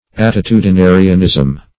Search Result for " attitudinarianism" : The Collaborative International Dictionary of English v.0.48: Attitudinarianism \At`ti*tu`di*na"ri*an*ism\, n. A practicing of attitudes; posture making.
attitudinarianism.mp3